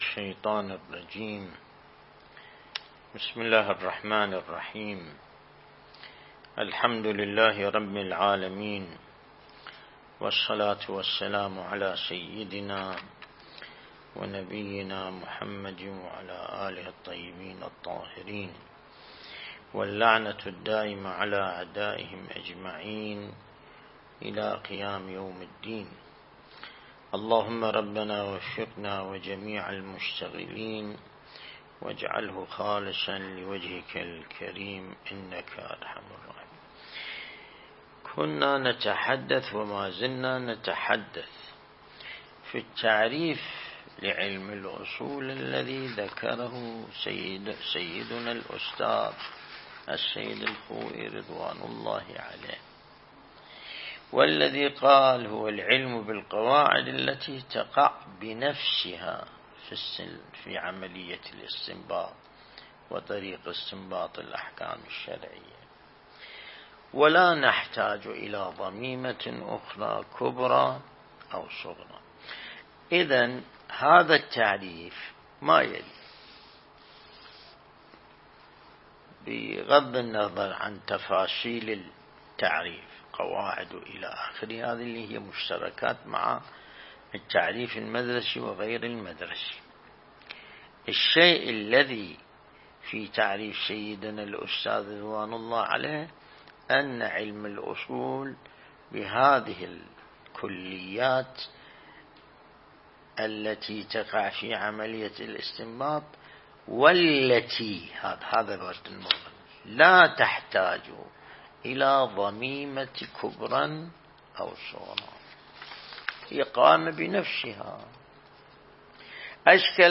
درس البحث الخارج الأصول (11)